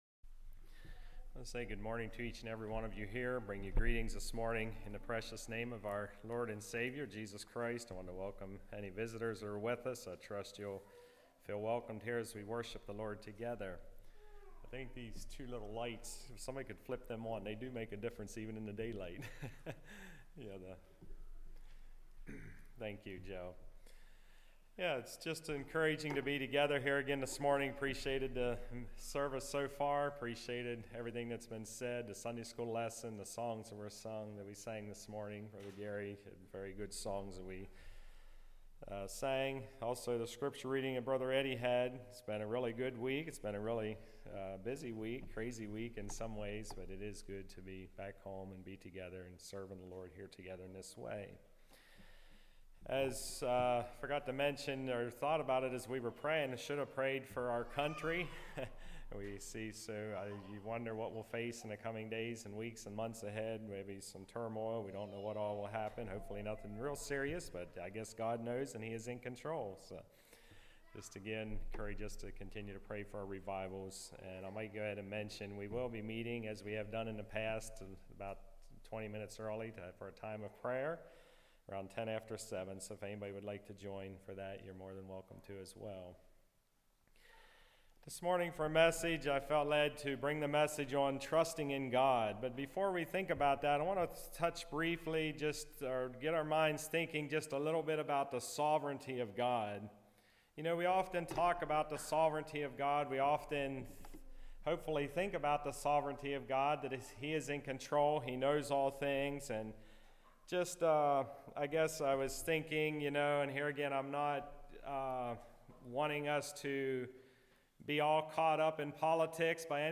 Service Type: Message